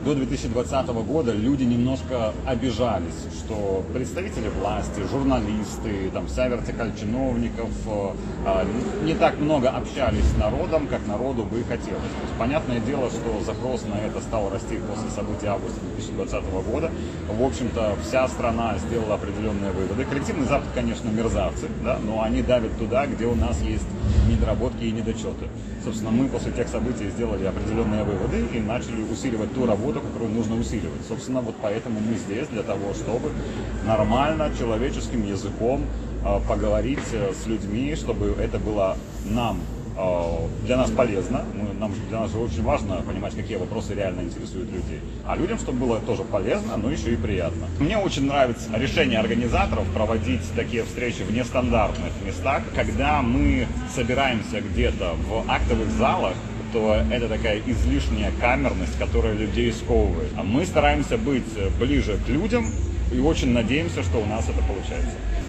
В рамках акции «Марафон единства» в Барановичах  состоялась «Знаковая встреча» с  известными спикерами.  На железнодорожном вокзале станции Барановичи-Полесские участники диалога обсуждали актуальные вопросы социальной политики, информационной безопасности, события, происходящие в мире. Министр информации Беларуси  Марат Марков и политический обозреватель ОНТ Игорь Тур затронули и провокационную тему «Диктатура: правда и мифы».